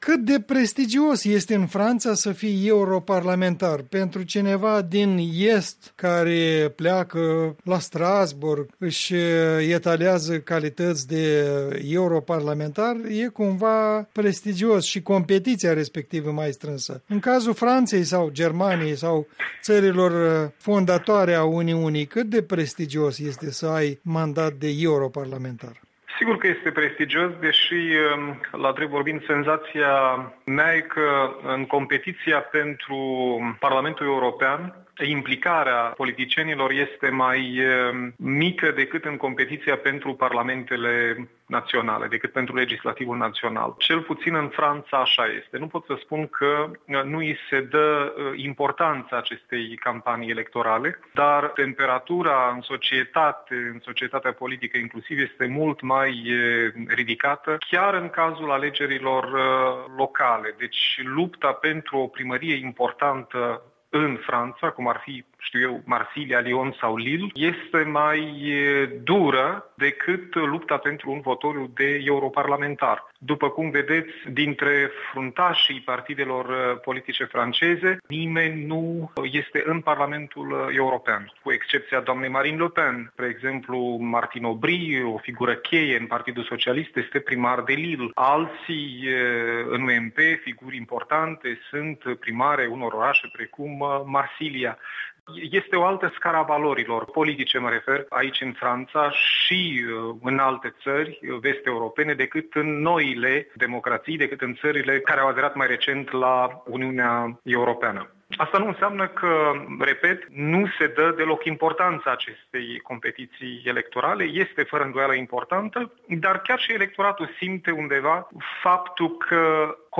Interviu cu ambasadorul Oleg Serebrian